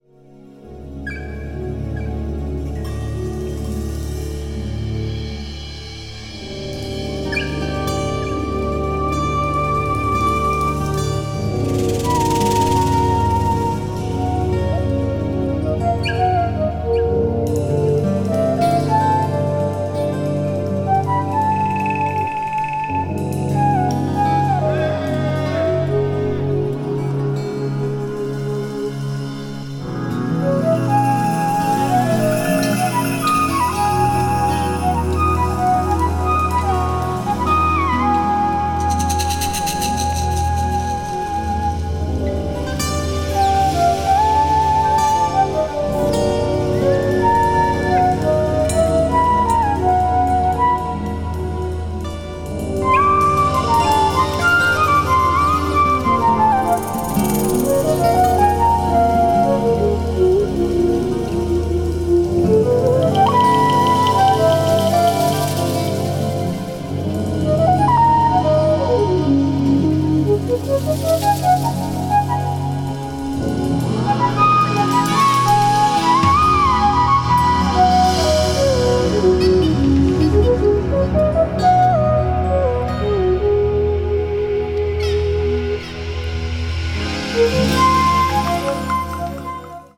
balearic   crossover   fusion   jazz groove   new age jazz